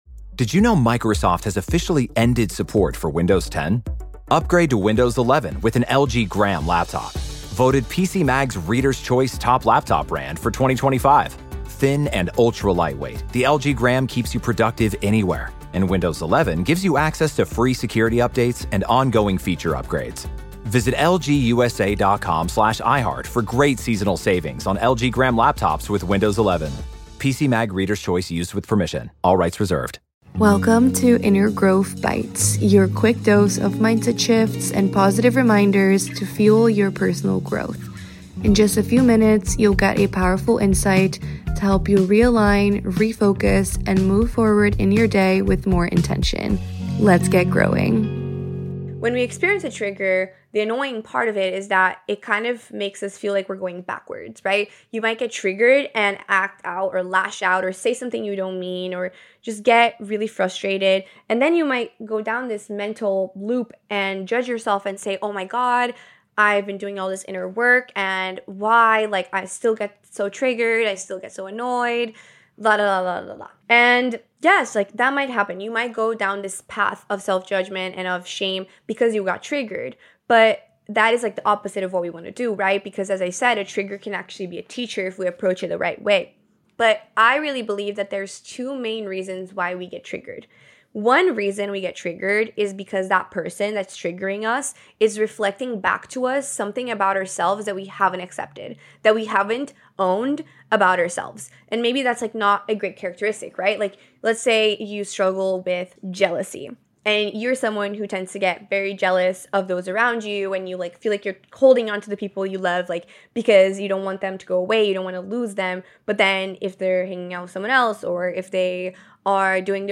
Here to fuel your personal evolution one podcast at a time, Inner Growth provides fun, real & deep interviews + conversations that strengthen your connection to self, elevate your mindset and help you find more self love.